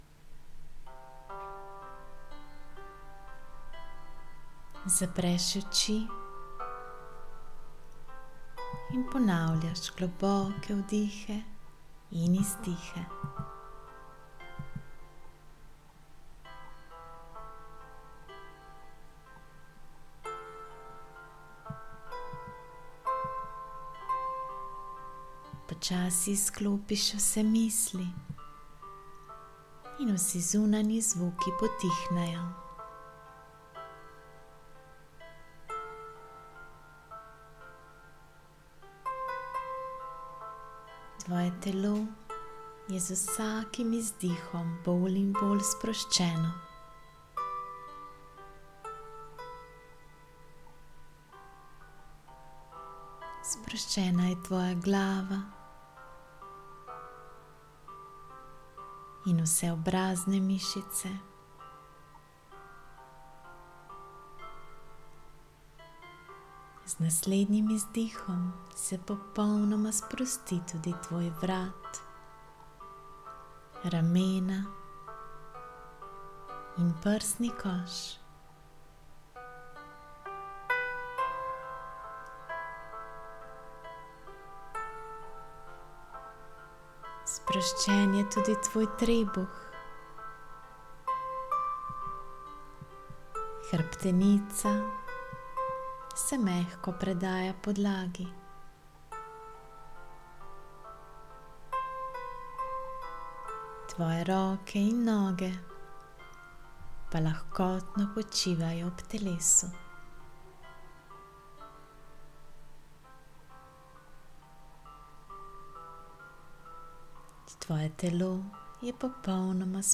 Zato le Tebi, ki prejemaš moja elektronska pisemca, podarjam čudovito meditacijo Novoletna namera , ki je namenjena poslušanju v mesecu decembru.